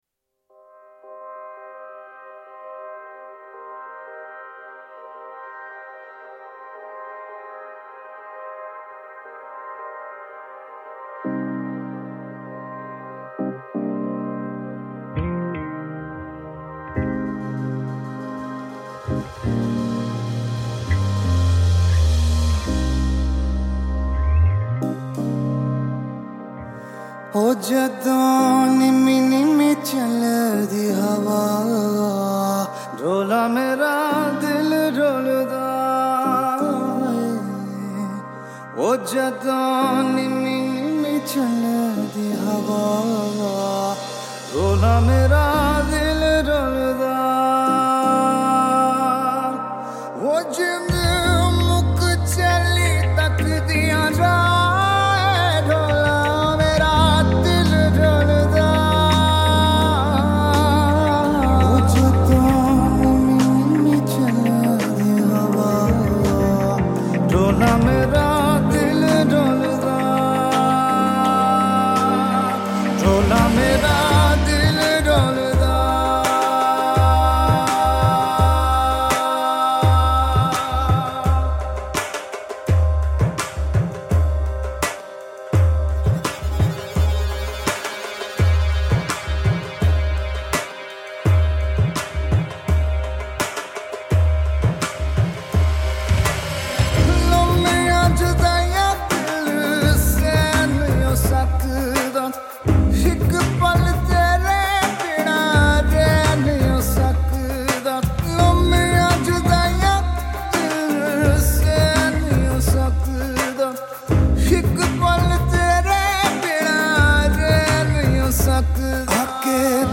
Vocals & Drums
Keyboards
Dhol
Guitar
Bass